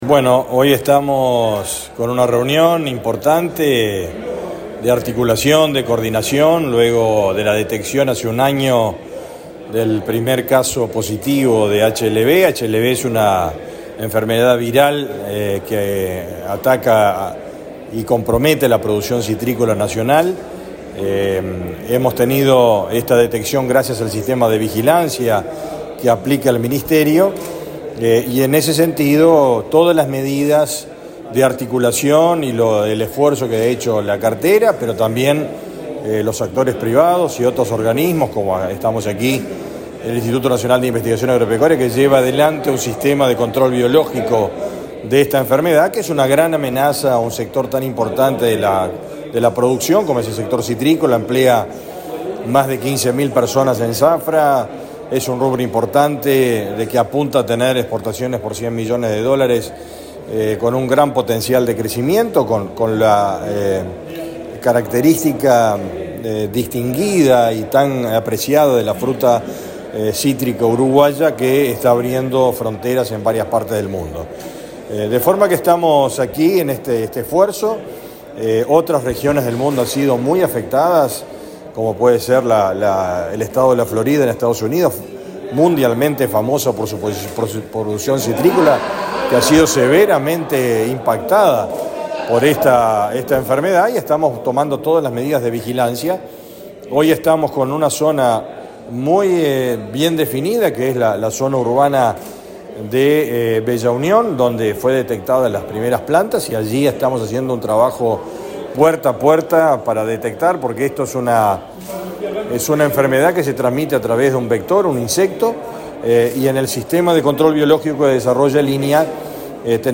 Entrevista al ministro de Ganadería, Fernando Mattos
Entrevista al ministro de Ganadería, Fernando Mattos 29/02/2024 Compartir Facebook X Copiar enlace WhatsApp LinkedIn El ministro de Ganadería, Fernando Mattos, dialogó con Comunicación Presidencial en Salto, durante su participación en una jornada de trabajo con técnicos del Instituto Nacional de Investigación Agropecuaria, realizada este jueves 29 en las instalaciones de ese organismo en Salto Grande.